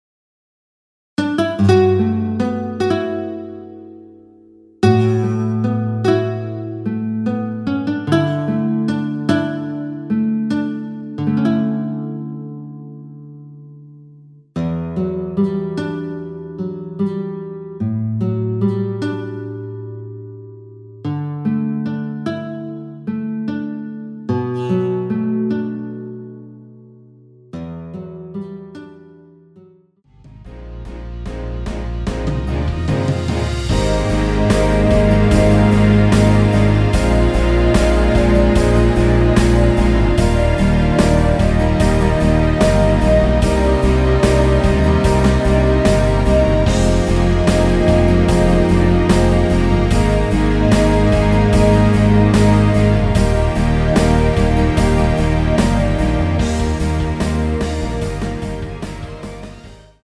원키에서(-2)내린 MR입니다.
앞부분30초, 뒷부분30초씩 편집해서 올려 드리고 있습니다.
중간에 음이 끈어지고 다시 나오는 이유는